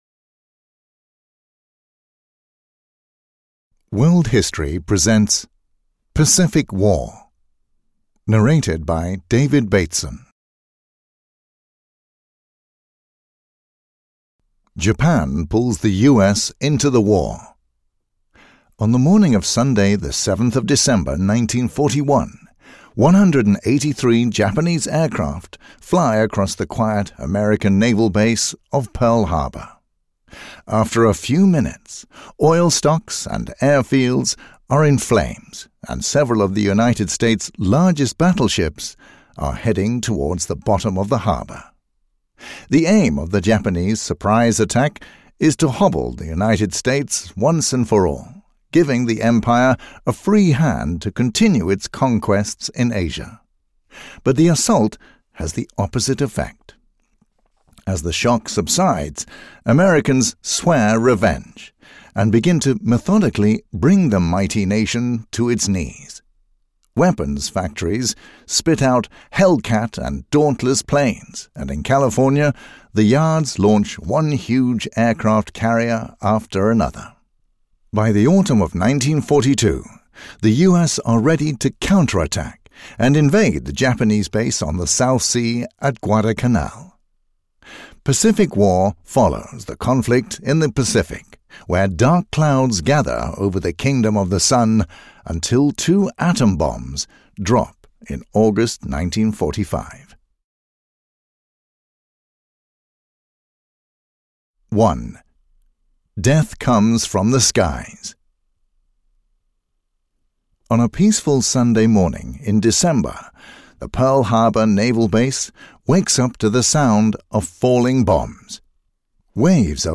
Pacific War (EN) audiokniha
Ukázka z knihy